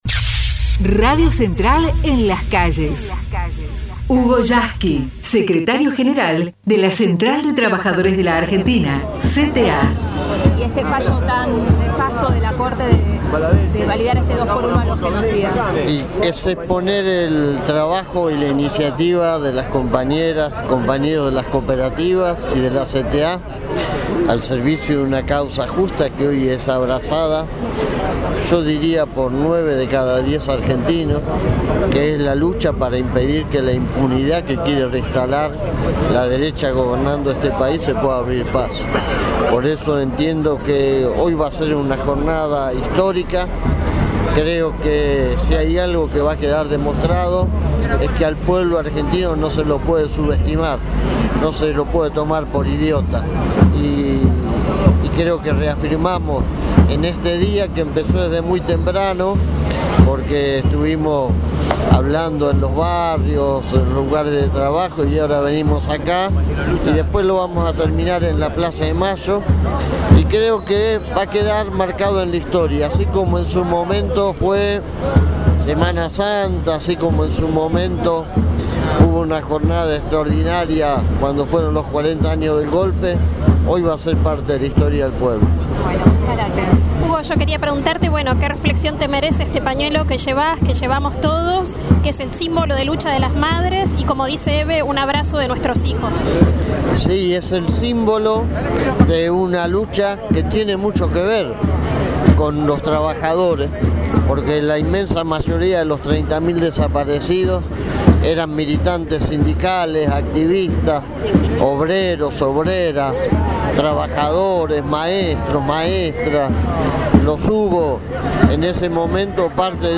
Hugo Yasky, Secretario General de la CTA en acto frente a Tribunales